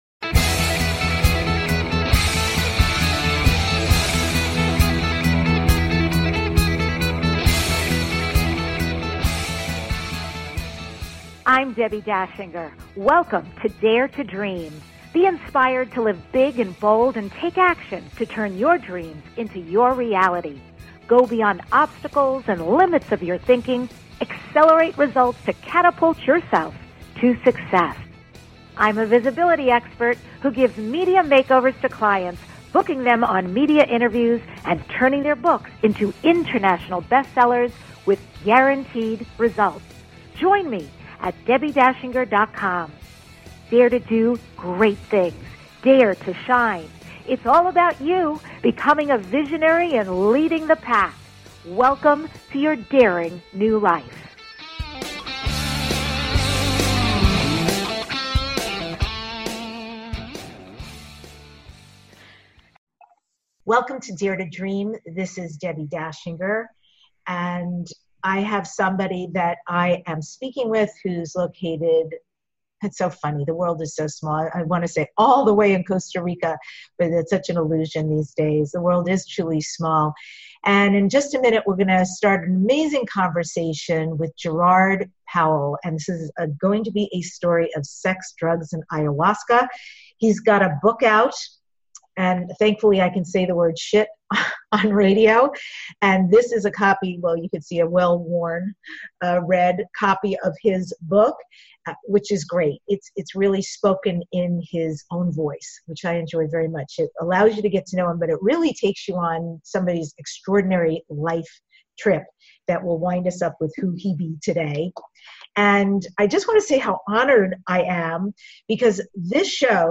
The award-winning DARE TO DREAM Podcast is your #1 transformation conversation.